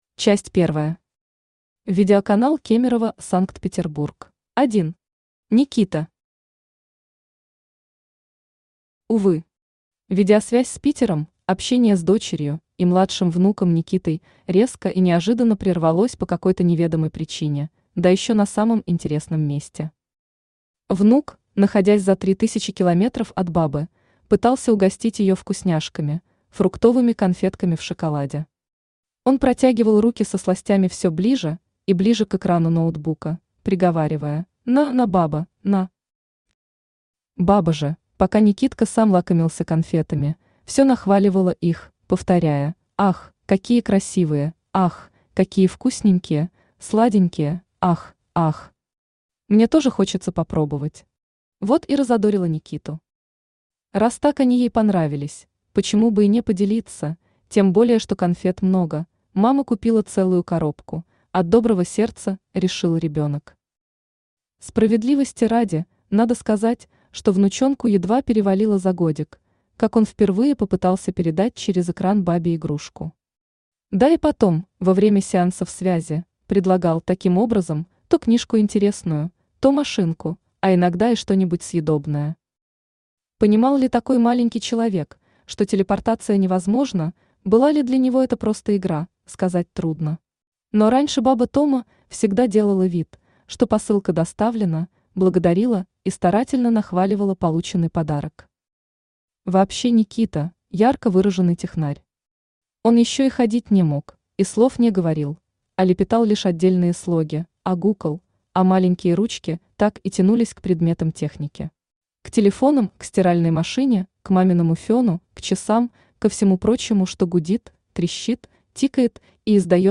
Аудиокнига Слава прогрессу!
Автор Тамара Антоновна Скрипаль Читает аудиокнигу Авточтец ЛитРес.